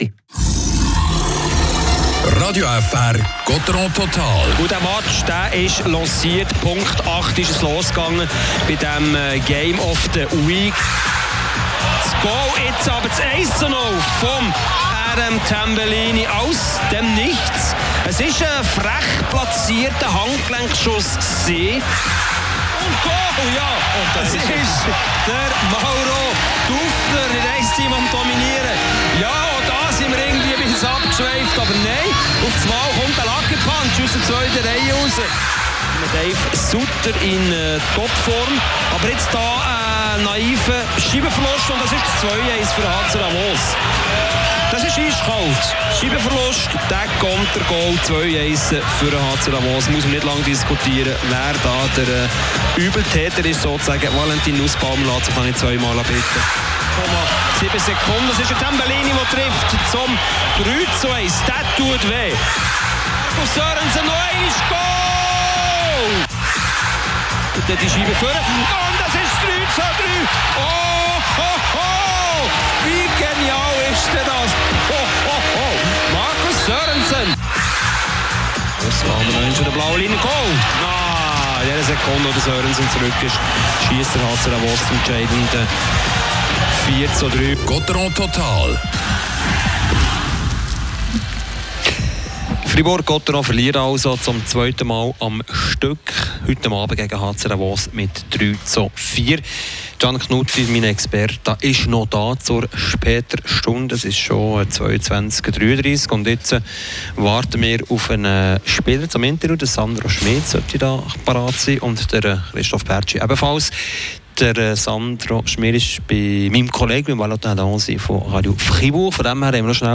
Die Interviews mit